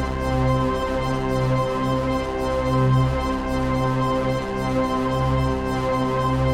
Index of /musicradar/dystopian-drone-samples/Tempo Loops/110bpm
DD_TempoDroneD_110-C.wav